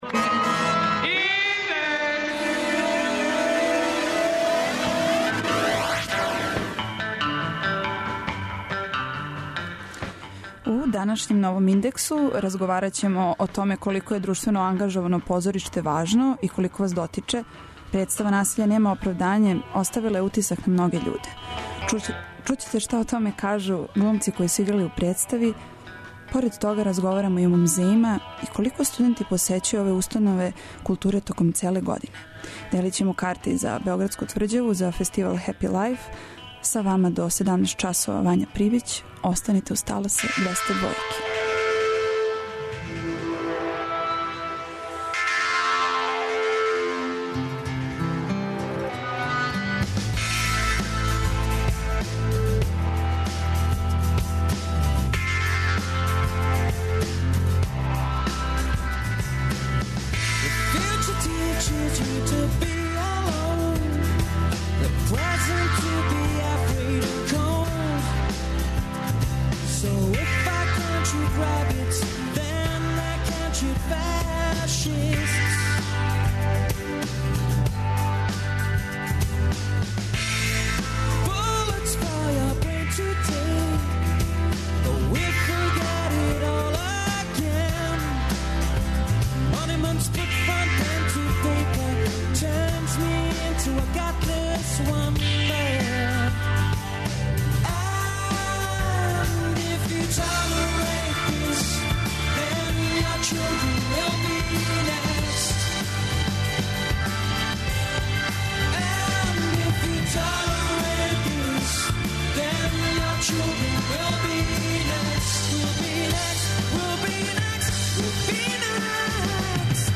Чућете шта о томе кажу глумци који су играли у представи. Поред тога разговарамо и о музејима и колико студенти посећују музеје током целе године.